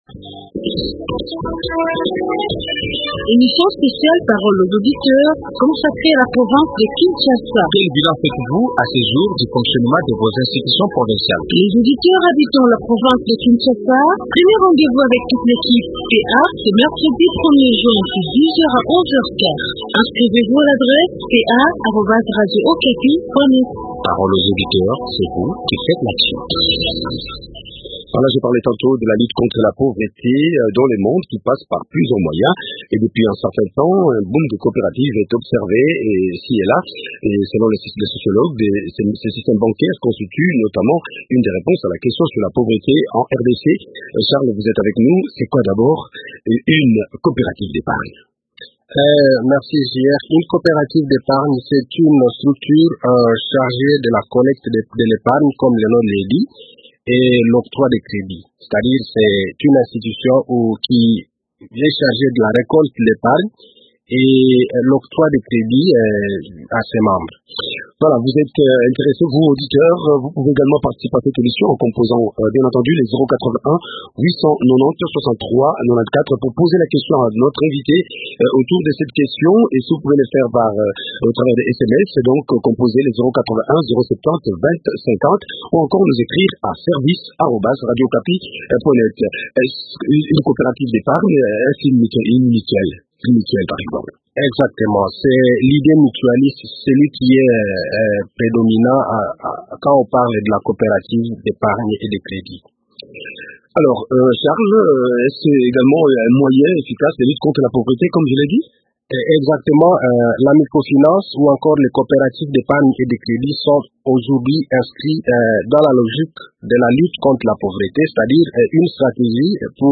D’autres détails dans cet entretien